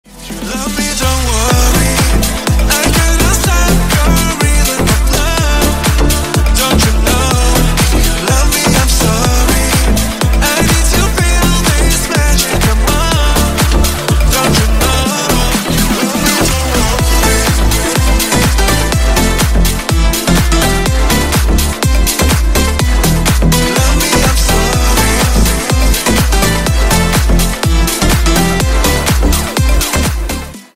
Клубные Рингтоны » # Громкие Рингтоны С Басами
Танцевальные Рингтоны